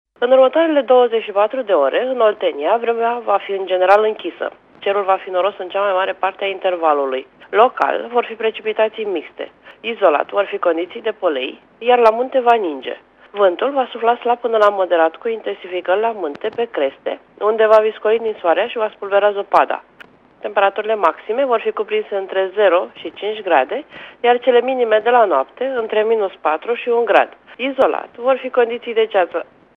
Prognoza meteo 19 ianuarie (audio)
Prognoza meteo pe Oltenia cu meteorolog